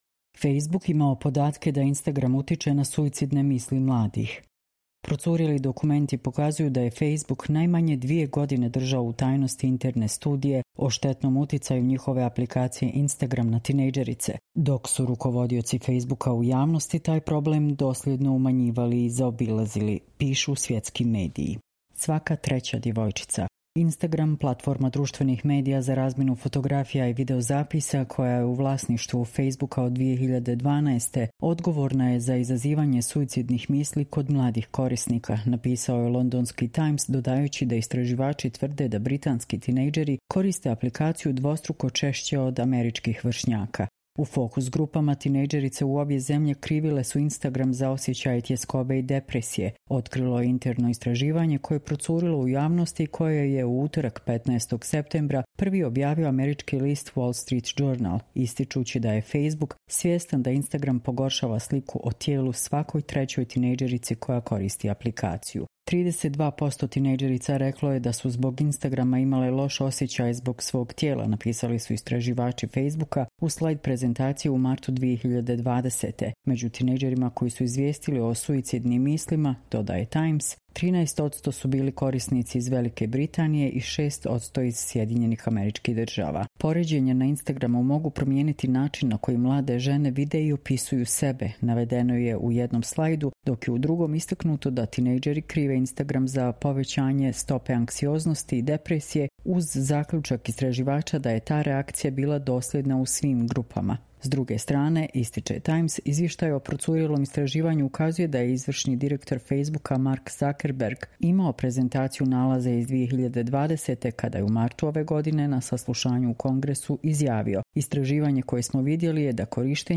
Čitamo vam: Facebook imao podatke da Instagram utiče na suicidne misli mladih